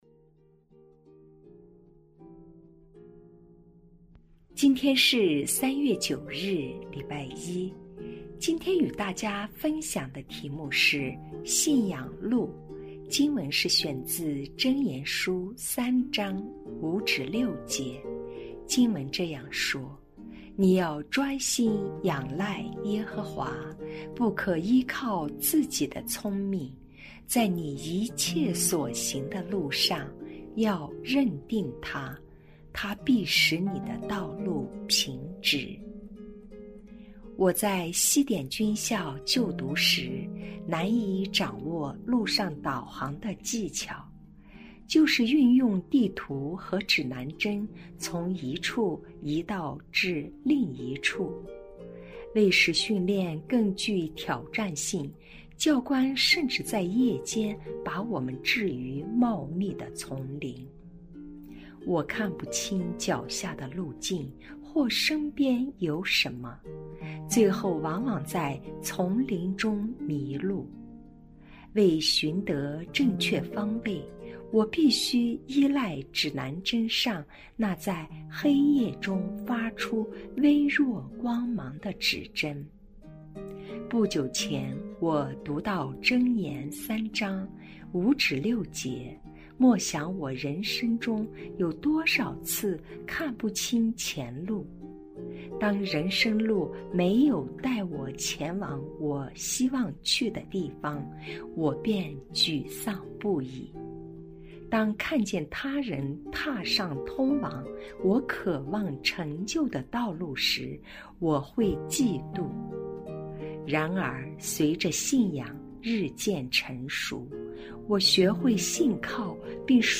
循道衞理聯合教會香港堂 · 錄音佈道組 Methodist Outreach Programme